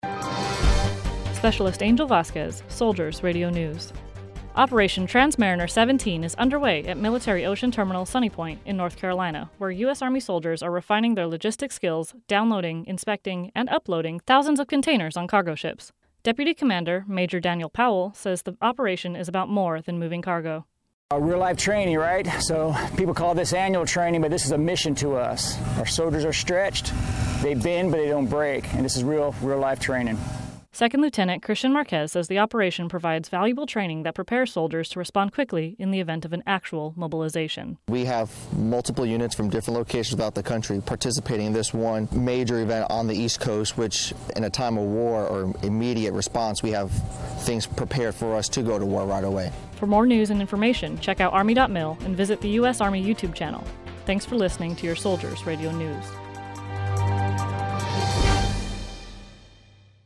Search Results Soldiers Radio News Defense Media Activity - Army Productions Video by Spc.